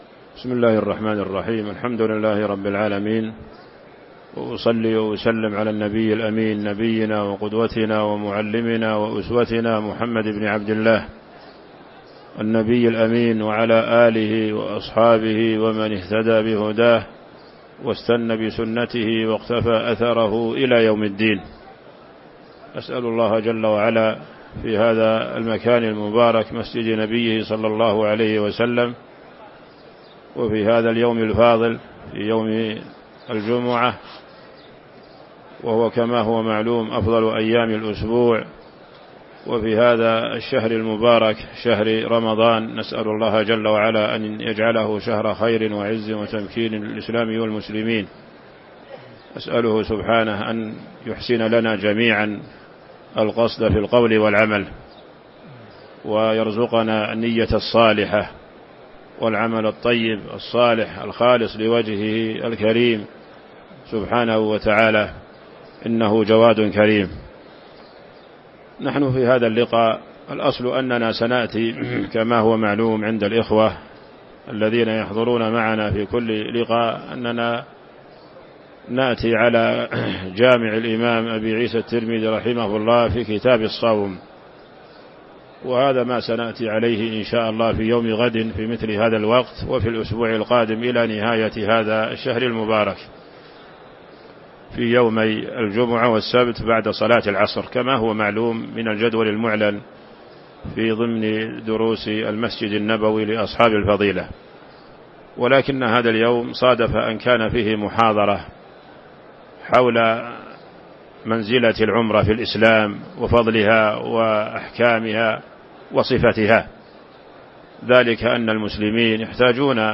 تاريخ النشر ٥ رمضان ١٤٤٥ هـ المكان: المسجد النبوي الشيخ